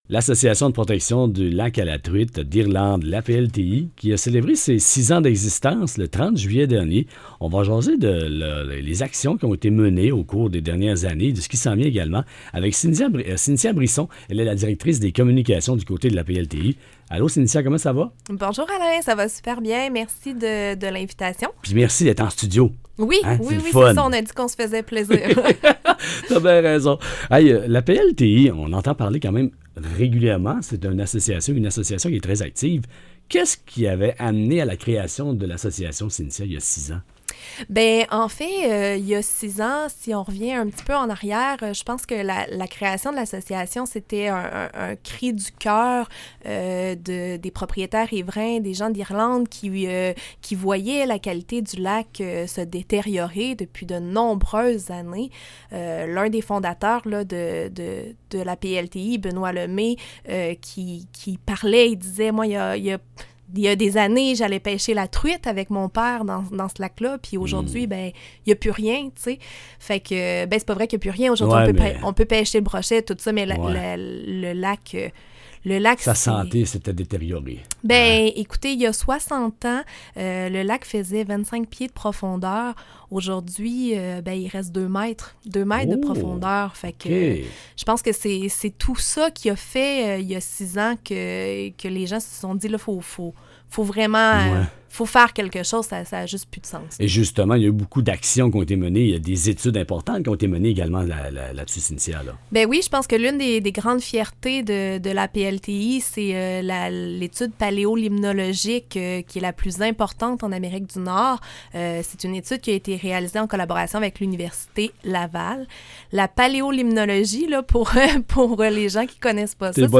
Notre nouvelle responsable des communications en entrevue à Plaisir 105.5